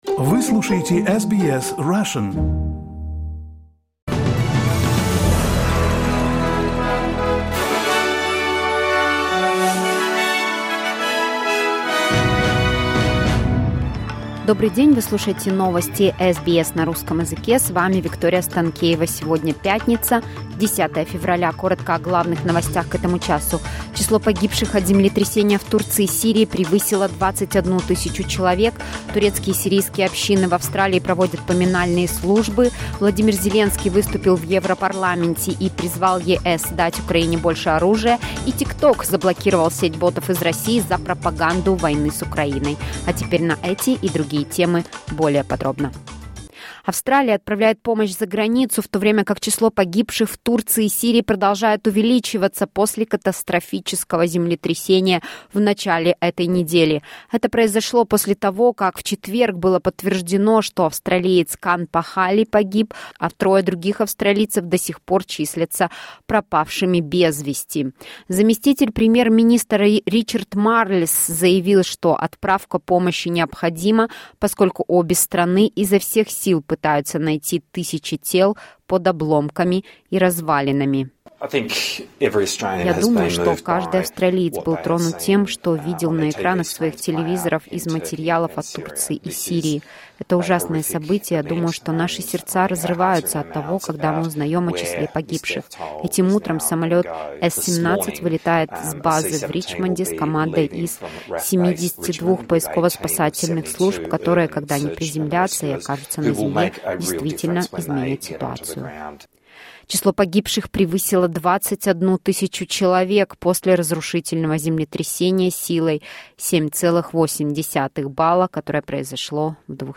SBS news in Russian — 10.02.2023